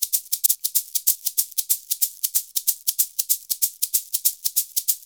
Index of /90_sSampleCDs/USB Soundscan vol.56 - Modern Percussion Loops [AKAI] 1CD/Partition B/07-SHAKER094